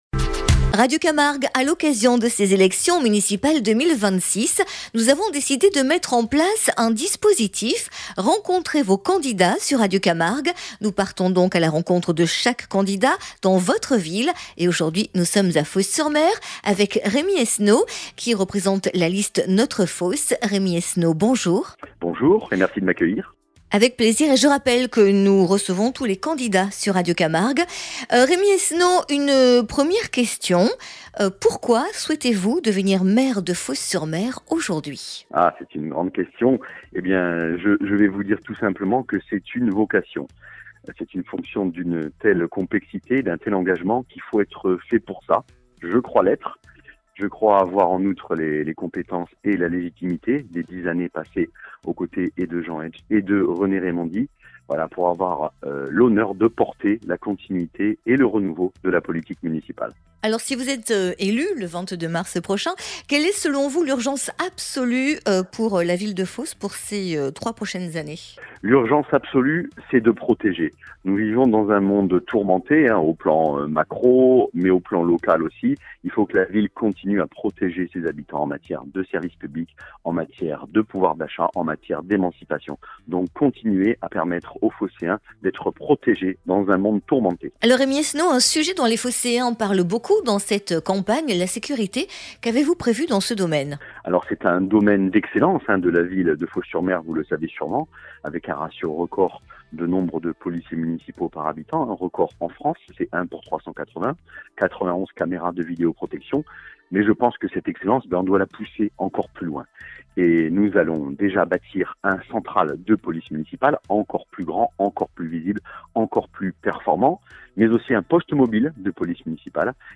Municipales 2026 : entretien